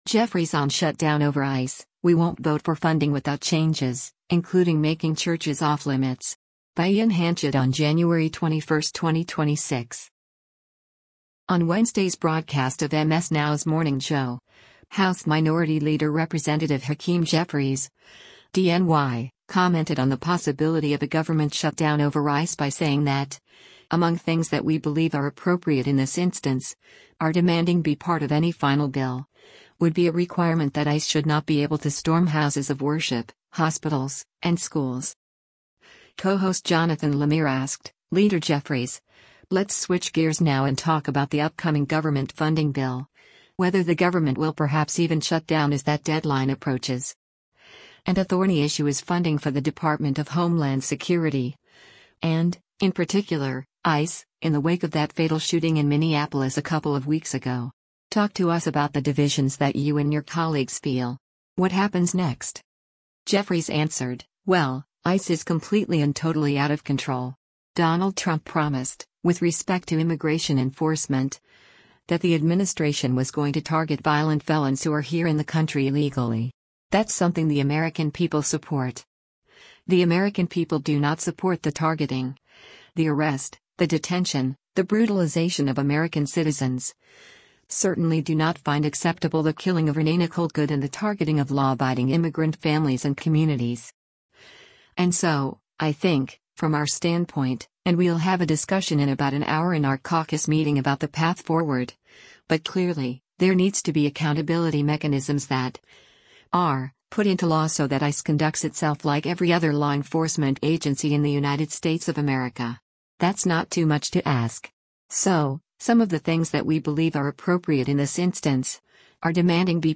On Wednesday’s broadcast of MS NOW’s “Morning Joe,” House Minority Leader Rep. Hakeem Jeffries (D-NY) commented on the possibility of a government shutdown over ICE by saying that, among “things that we believe are appropriate in this instance, are demanding be part of any final bill,” would be a requirement that “ICE should not be able to storm houses of worship, hospitals, and schools.”